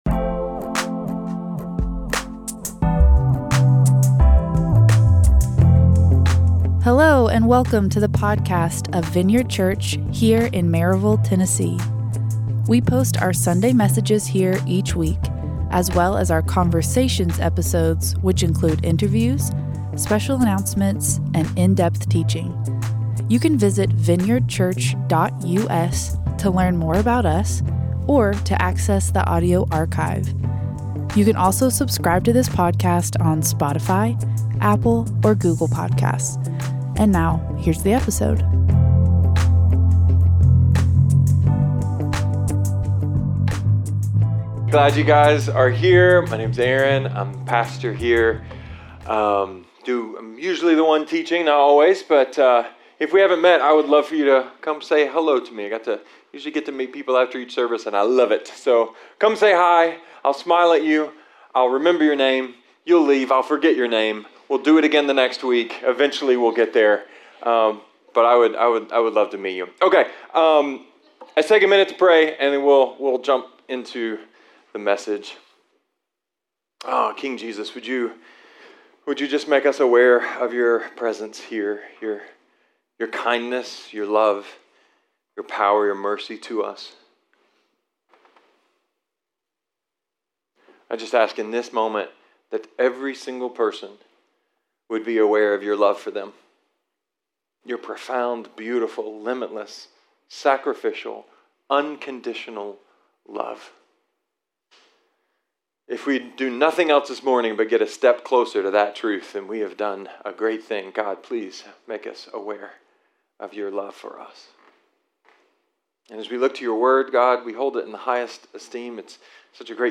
A sermon about God’s love, God’s generosity… and the fact that he’s earned our trust.